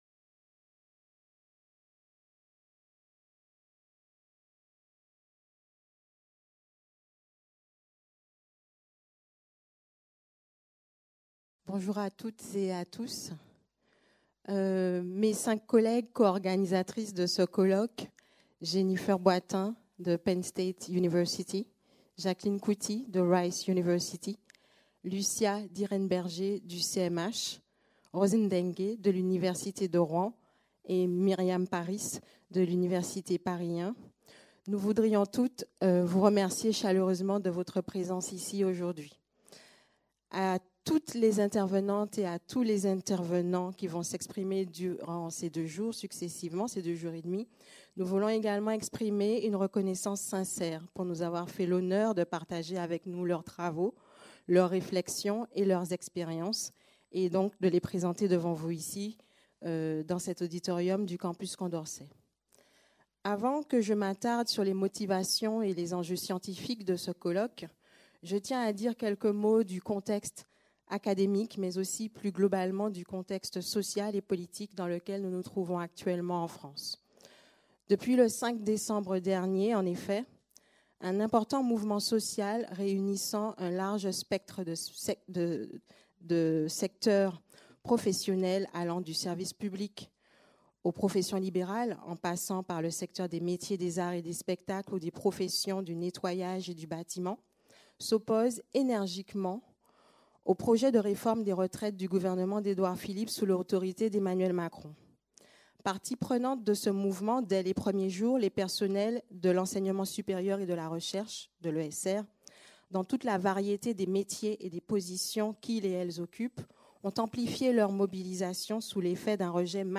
Allocution inaugurale | Canal U